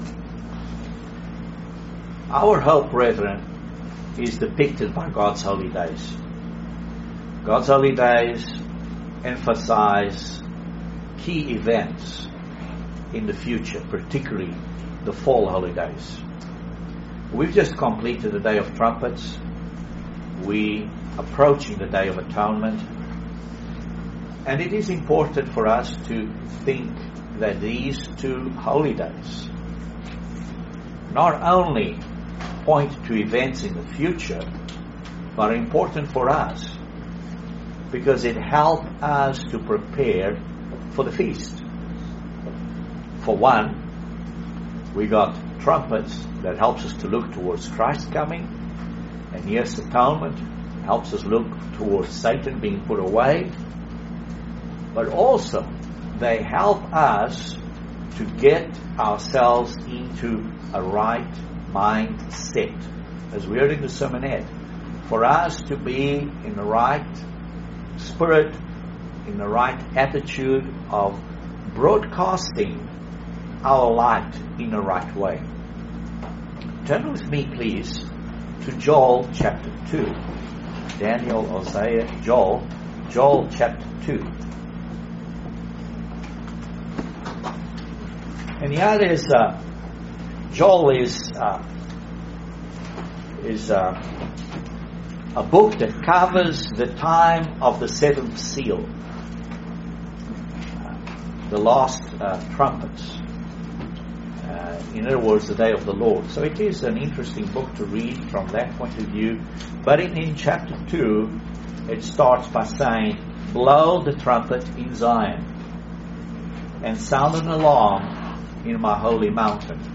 Excellent sermon on God's fall Holy Days. Join us for this interesting study on the day of Atonement, and its significance .